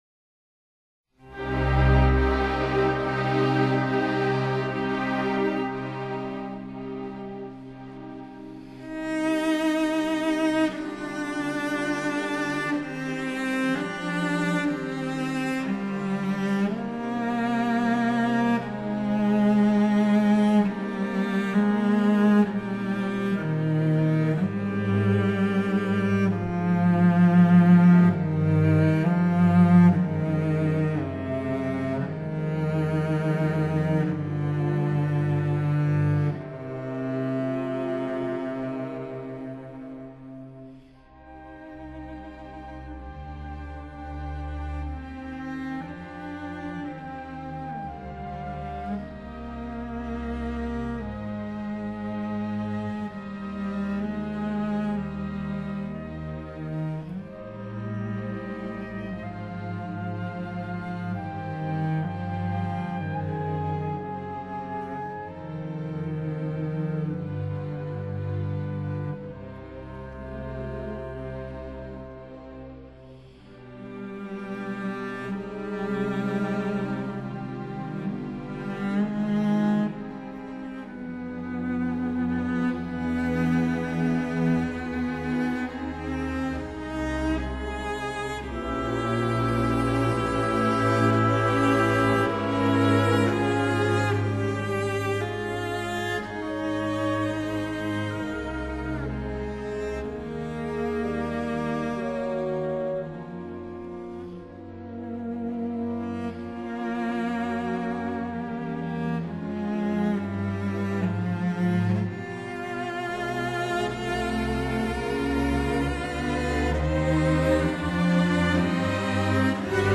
for cello & orchestra
cello